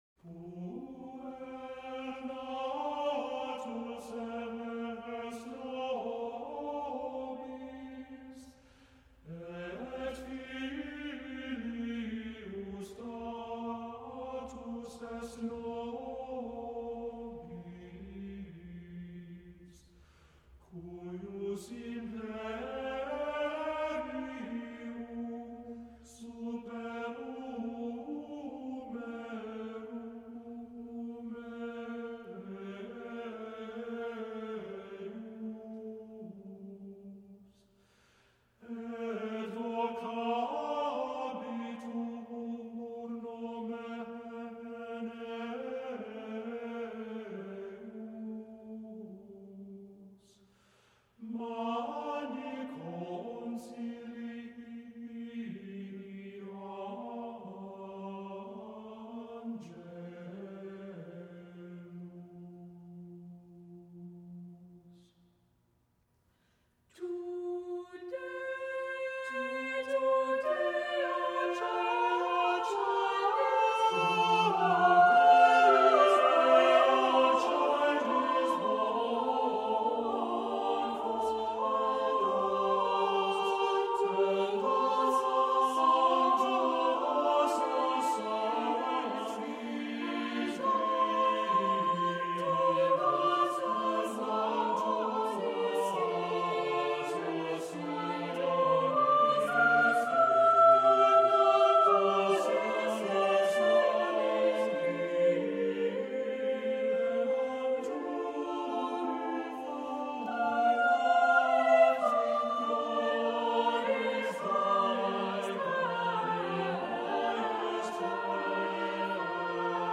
Accompaniment:      A Cappella
Music Category:      Early Music
Includes chant setting of Christmas introit.